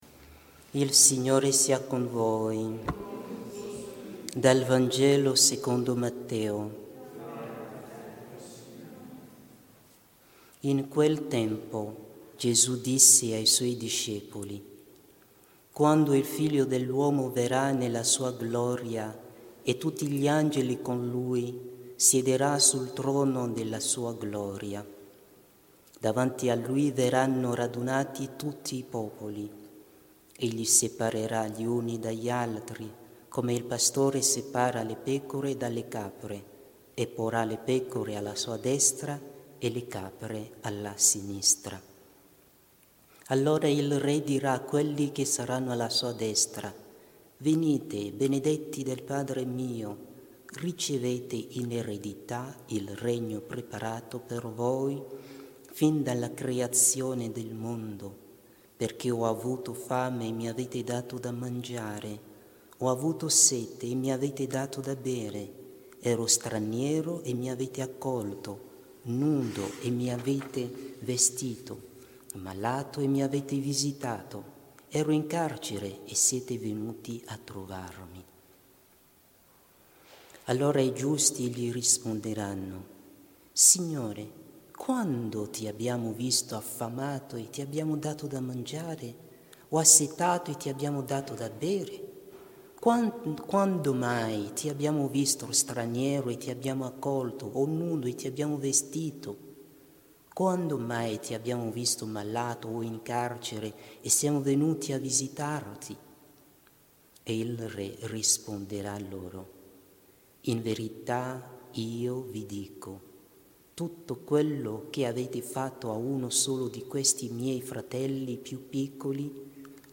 Download - Omelia 27 Agosto 2024, SANTA MONICA | Podbean